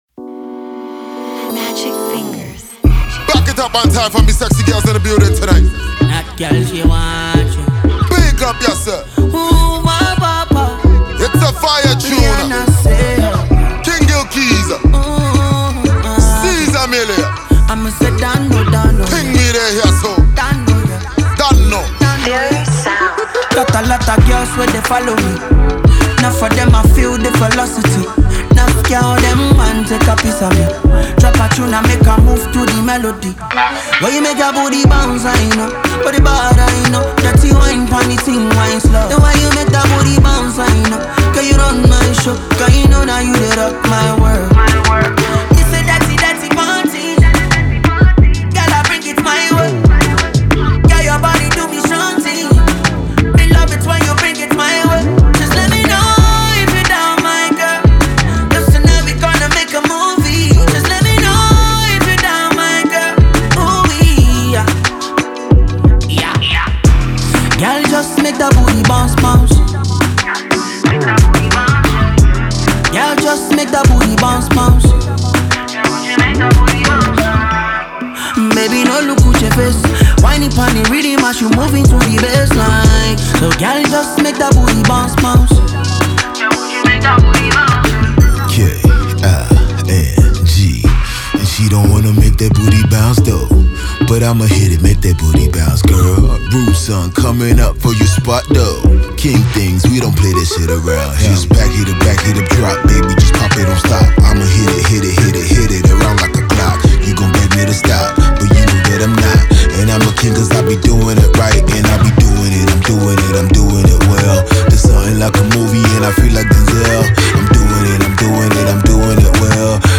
drops major heat on a new track featuring new school rappers
giving it a vibrant new school wave and a groovy feel
This club banger